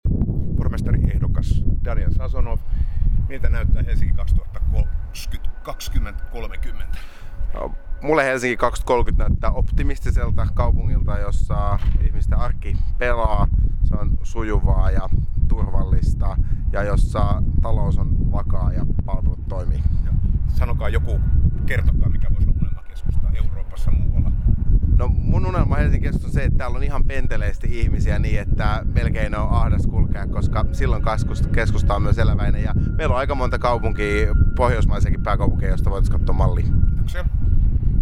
Pormestaripaneelissa Sofia Helsingissä kuultiin 26.3. ehdokkaita.